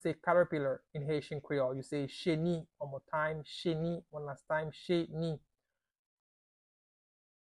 Pronunciation:
Listen to and watch “Cheni” audio pronunciation in Haitian Creole by a native Haitian  in the video below:
How-to-say-Caterpillar-in-Haitian-Creole-Cheni-pronunciation-by-a-Haitian-teacher.mp3